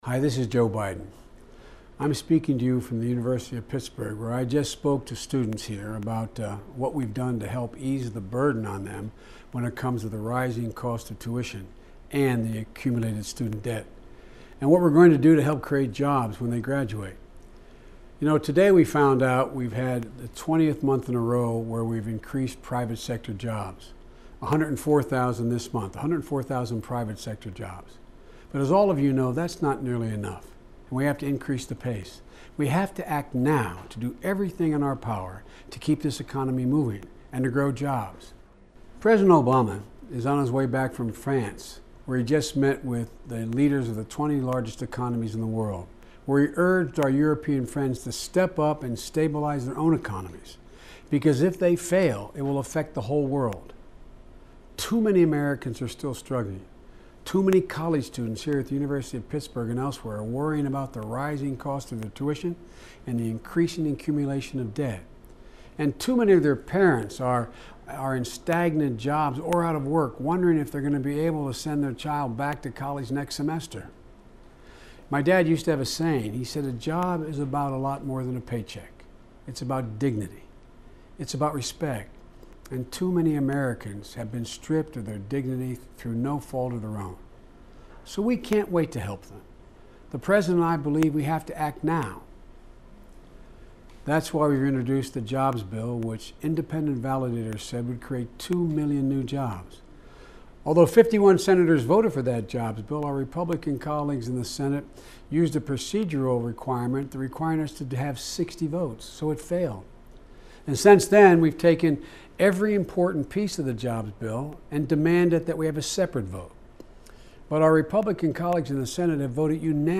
Remarks of Vice President Joe Biden
Pittsburgh, Pennsylvania